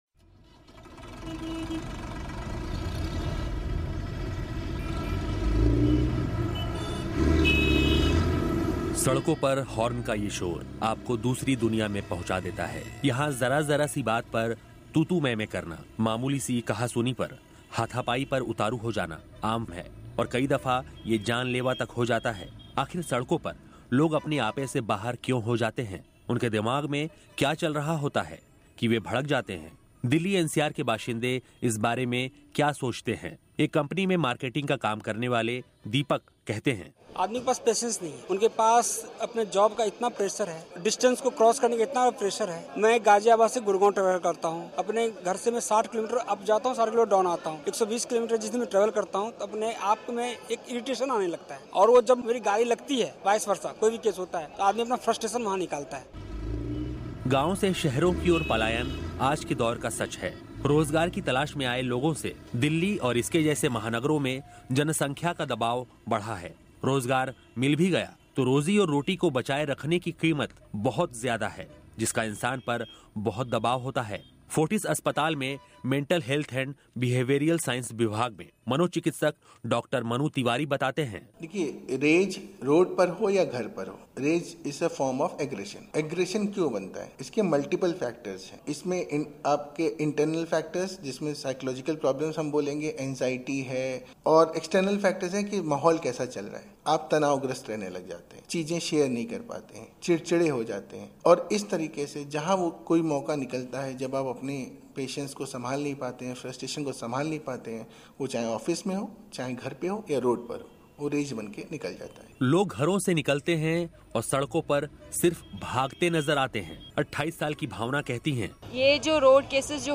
ये रिपोर्ट.